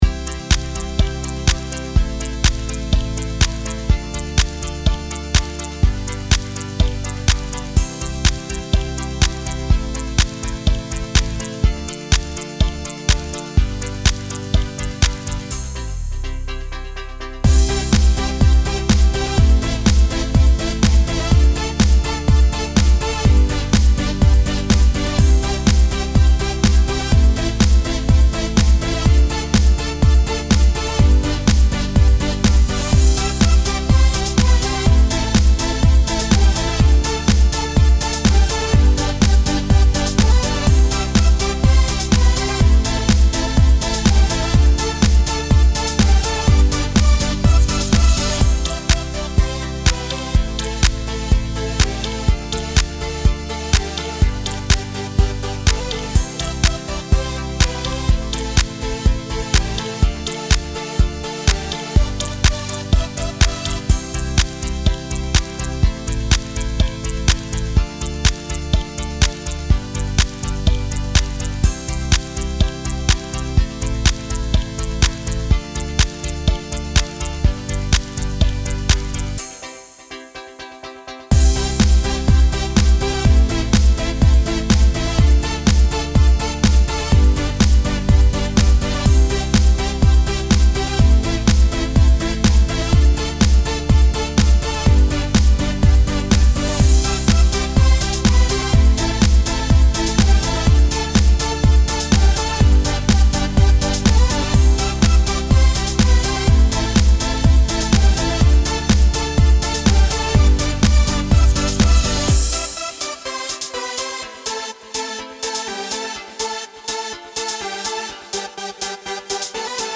Home > Music > Pop > Electronic > Bright > Running